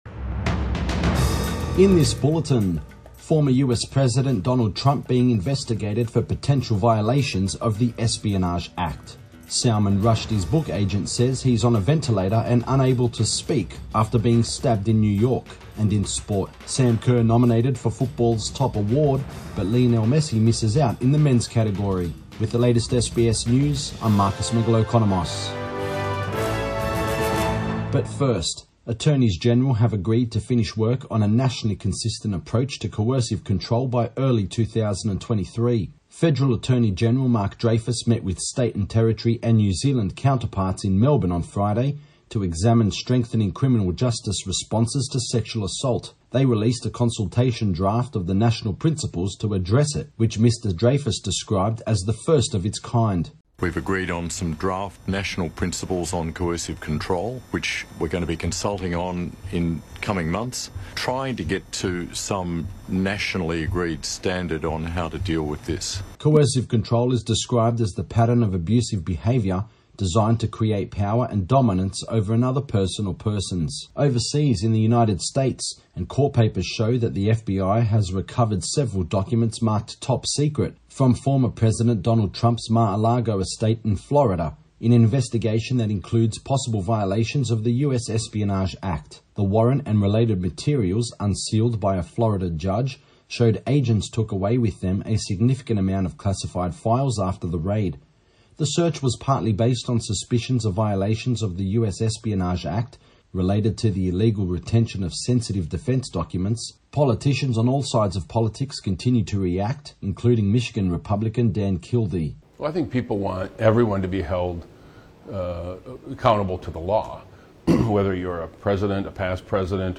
PM bulletin 13 August 2022